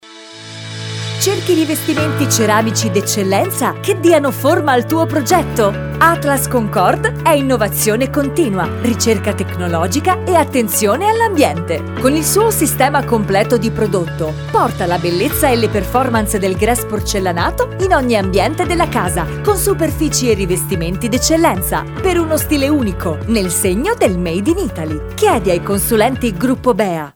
AtlasConcordeSpot.mp3